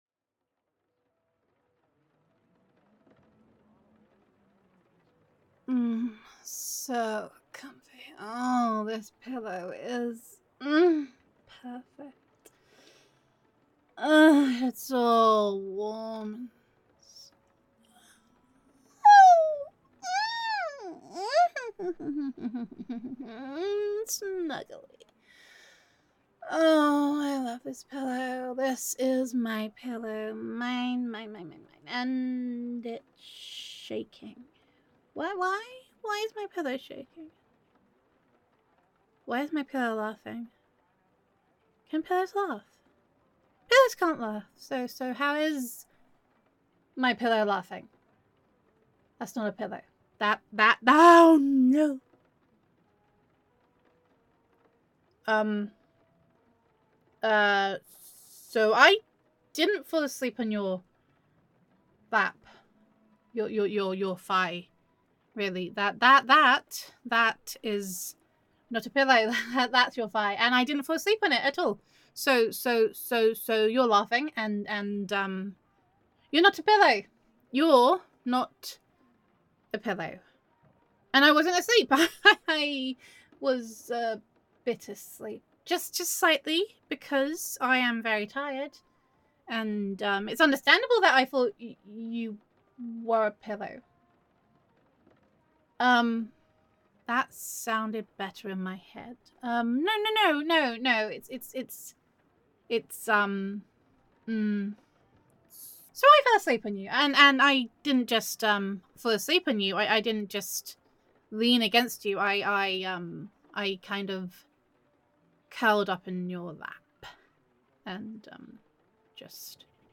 [F4A] You Make a Really Good Pillow
[Best Friend Roleplay]
And you ramble really well, always makes it feel so comforting.